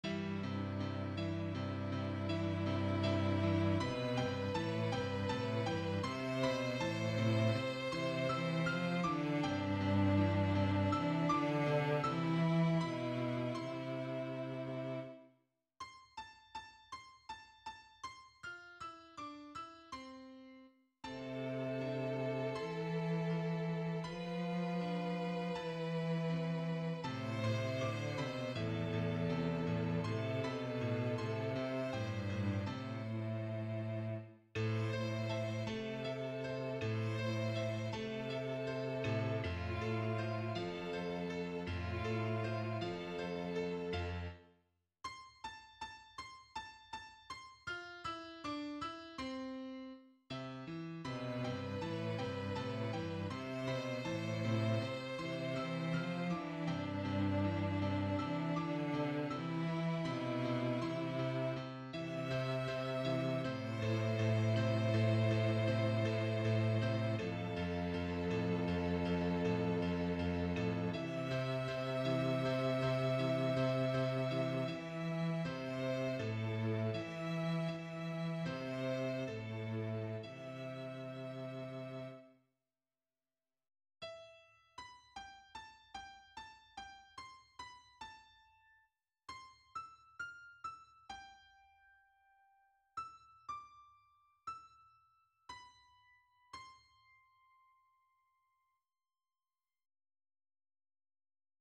Loved And Lost - Chamber Music
It isn’t to complex and like usual, I’m always welcoming to advice on how to make the harmony a bit more complicated.